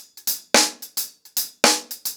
DaveAndMe-110BPM.25.wav